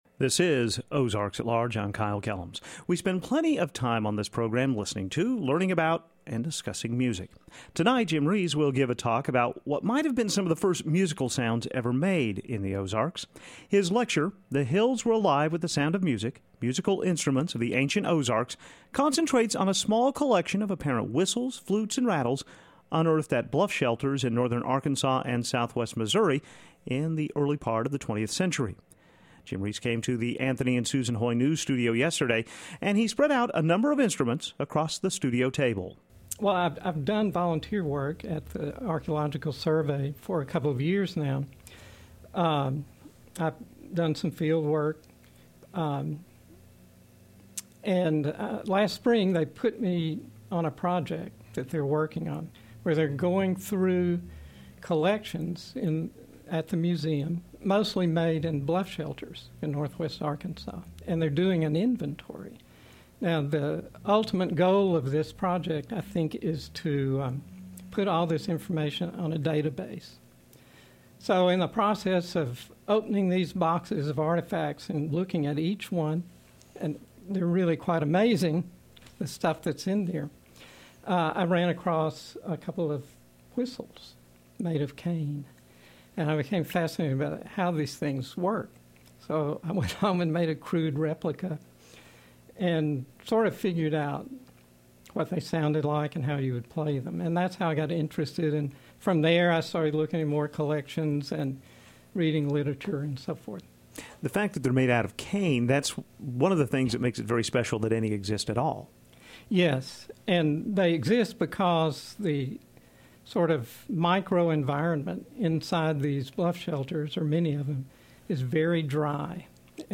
Ancient Flutes
in our studio